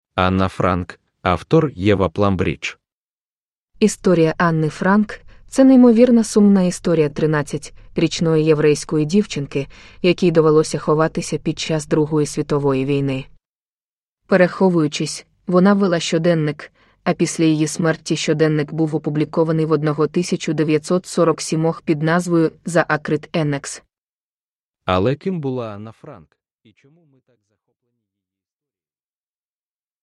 Anne Frank – Ljudbok – Laddas ner
Uppläsare: Reedz Audiobooks